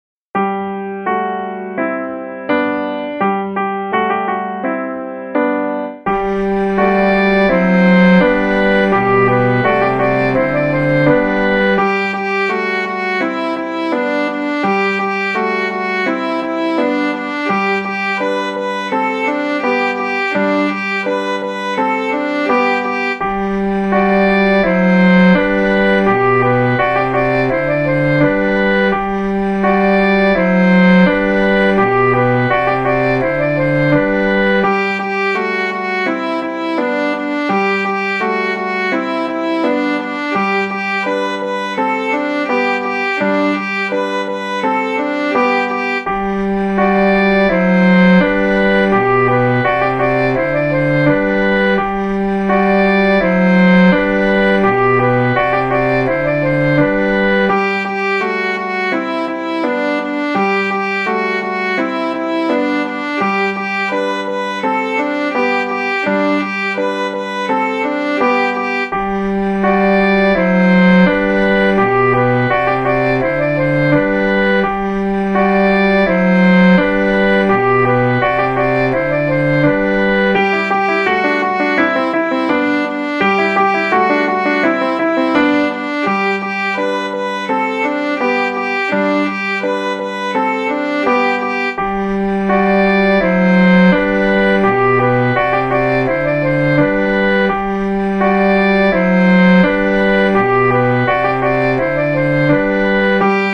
Для початку поспіваємо веселу пісню про велику ягідку – гарбуз!
Караоке.